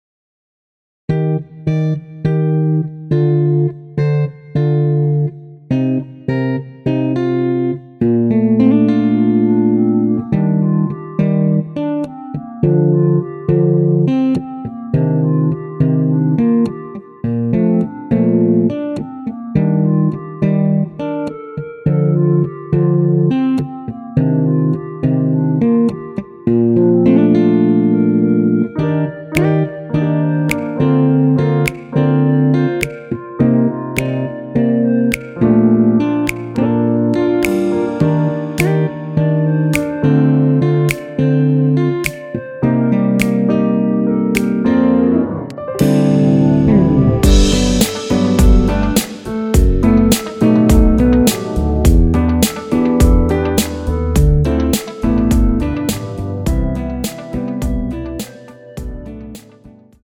원키에서(-1)내린 멜로디 포함된 MR입니다.
Bb
앞부분30초, 뒷부분30초씩 편집해서 올려 드리고 있습니다.
중간에 음이 끈어지고 다시 나오는 이유는